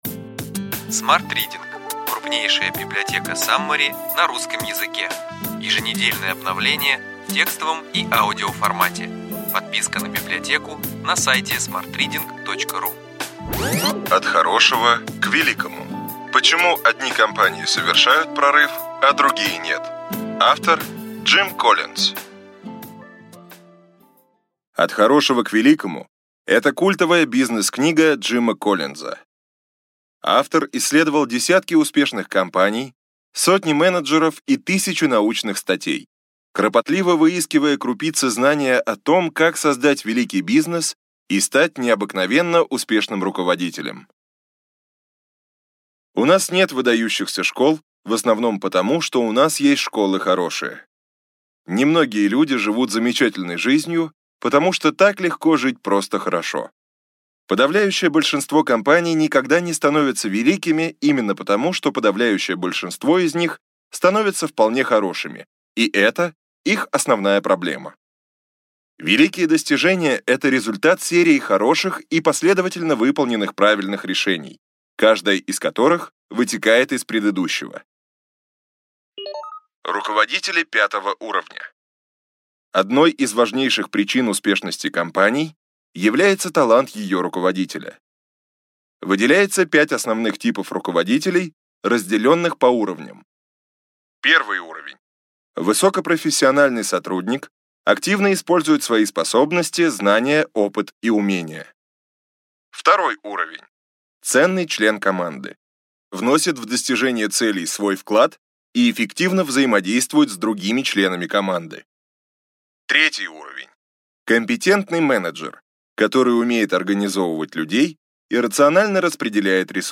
Аудиокнига Ключевые идеи книги: От хорошего к великому.